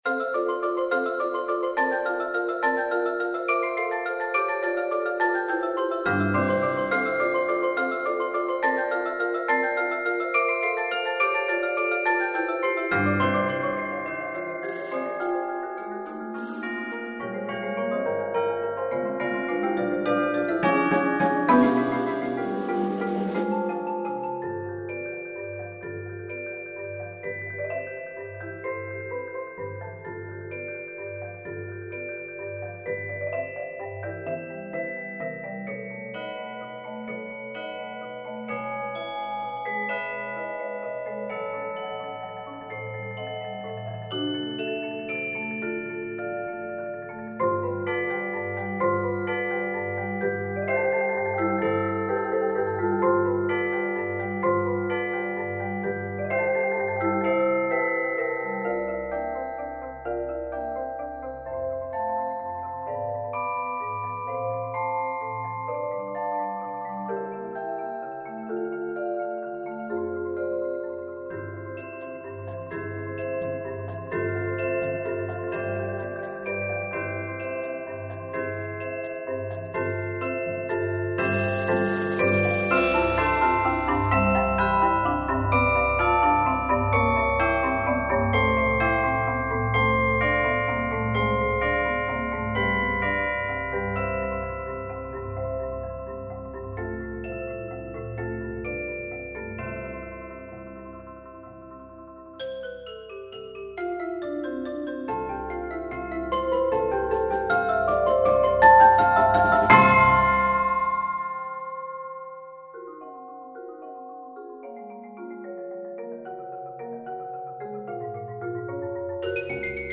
Genre: Percussion Ensemble
Glockenspiel
Chimes
Vibraphone 1
Xylophone
Marimba 1 [4.3-octave]
Timpani [4 drums]
Percussion 2: Crash Cymbals (shared), Tambourine